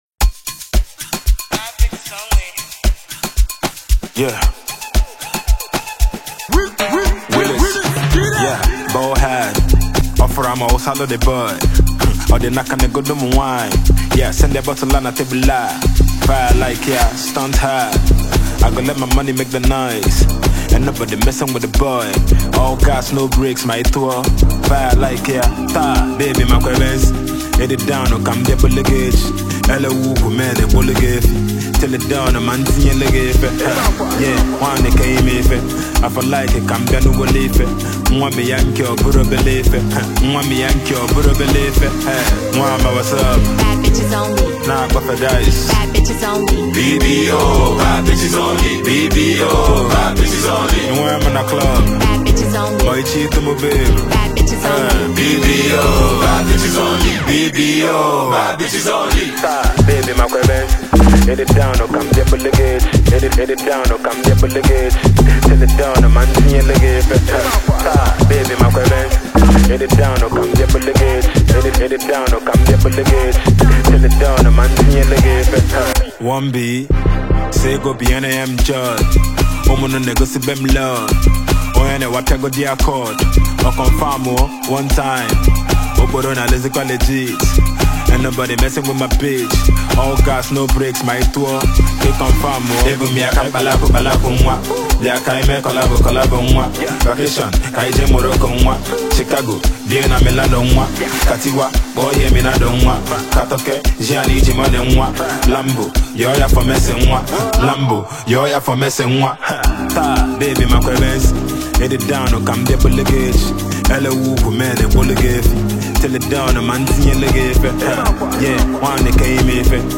rapper and singer